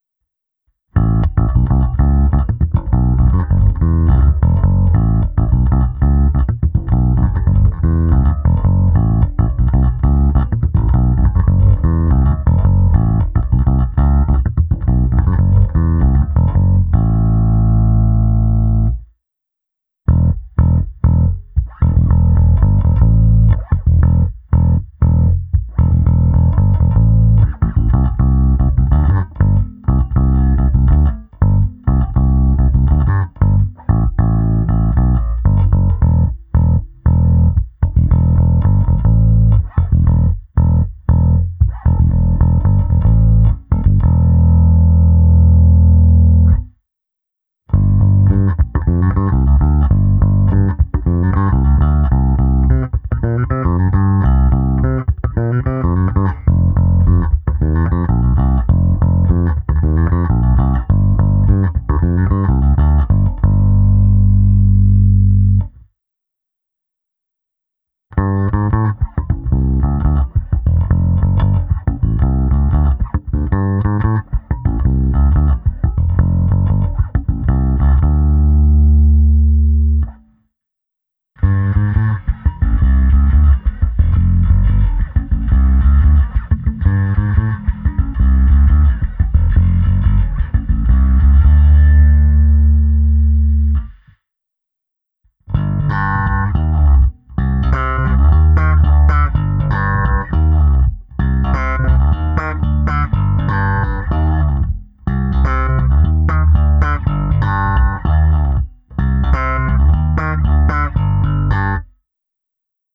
Ale jinak je zvuk výborný, plný, čistý, vrčí, ale je podstatně hodnější než naprděný Music Man.
Nahrávka se simulací aparátu včetně ukázky zkreslení a hry slapem